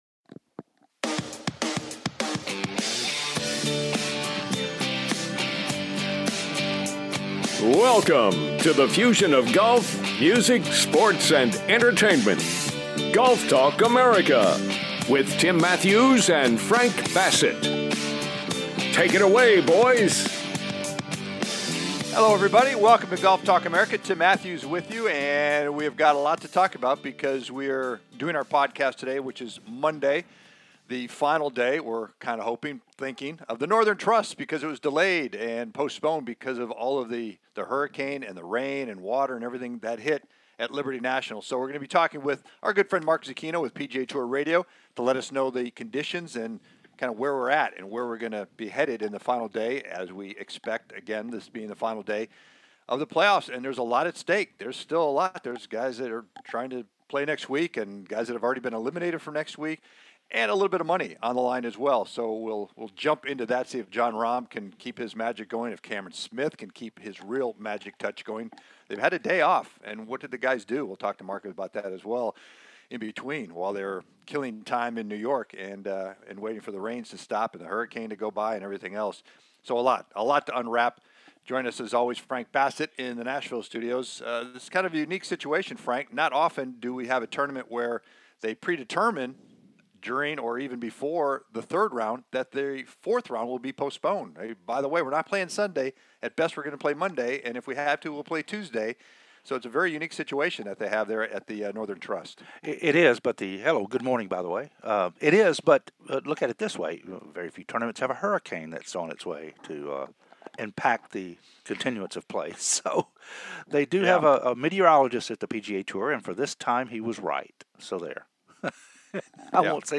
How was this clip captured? "LIVE" FROM THE NORTHERN TRUST AT LIBERTY NATIONAL GOLF CLUB